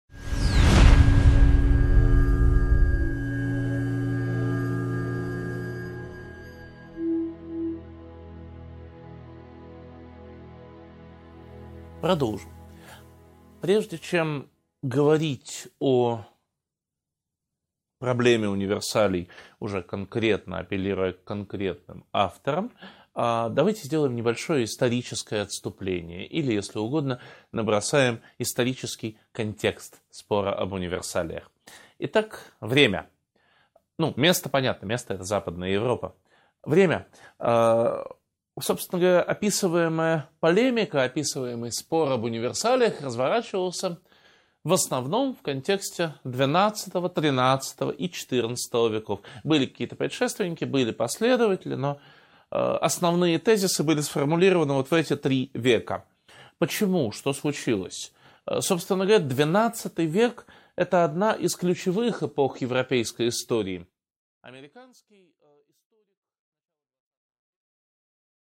Аудиокнига 7.2 Спор об универсалиях: контекст | Библиотека аудиокниг